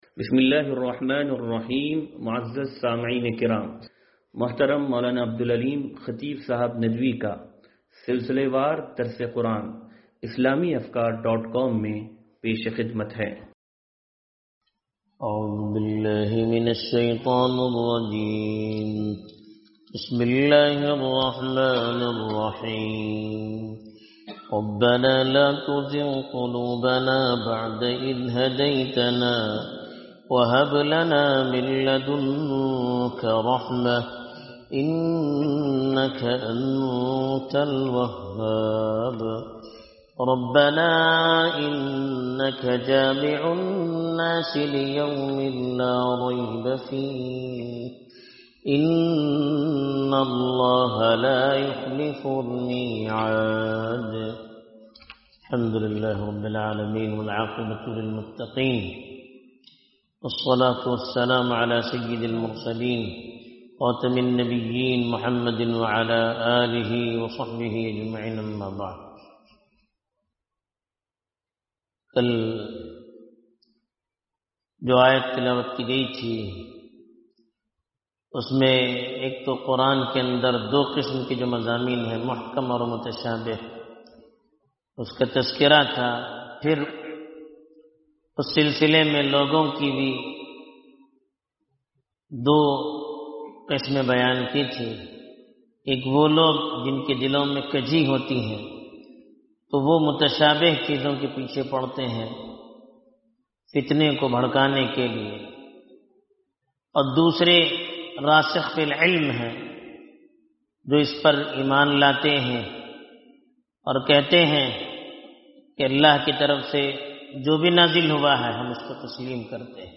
درس قرآن نمبر 0223